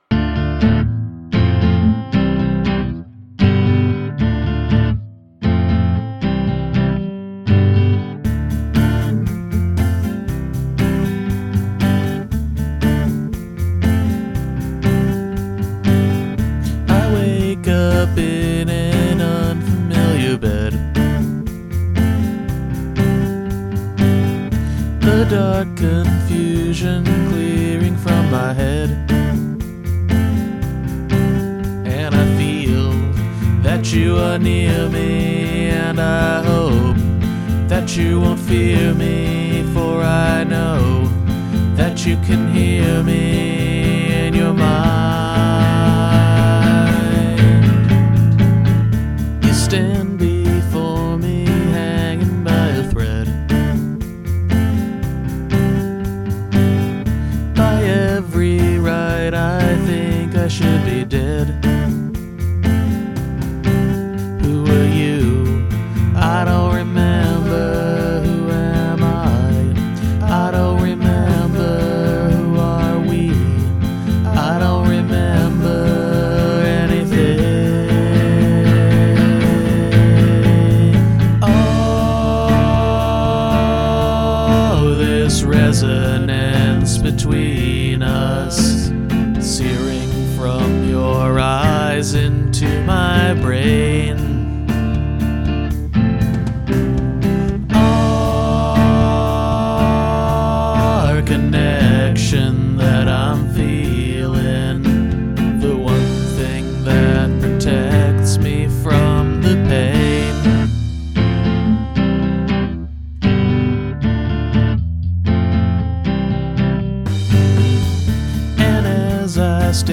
Riff: Dm F C G (strum) / / / .